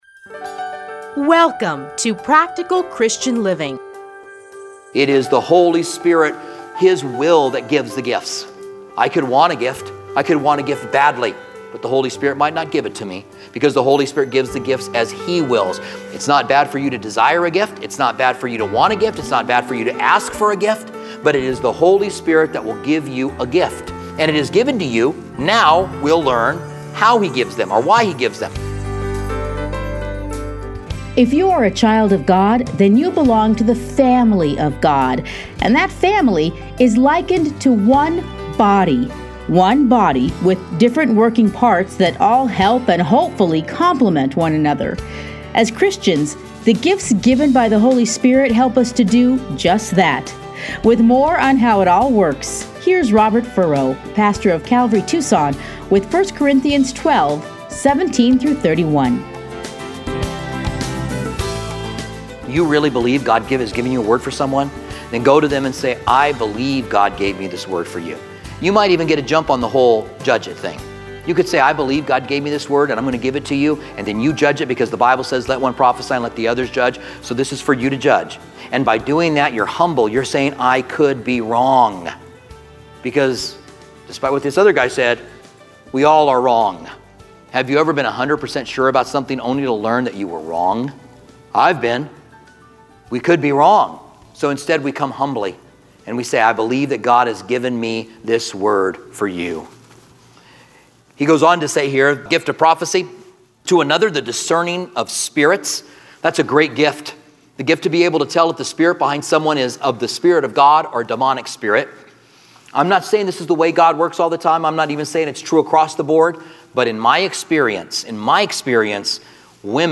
Listen here to a teaching from 1 Corinthians.